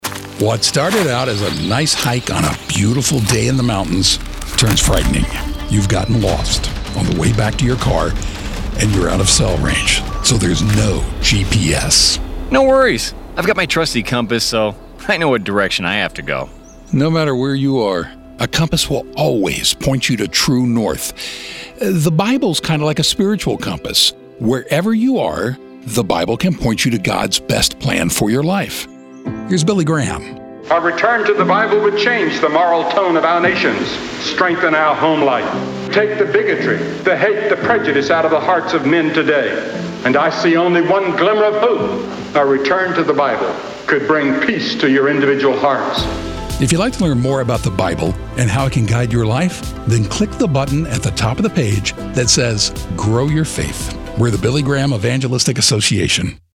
Learn more in this short sermon.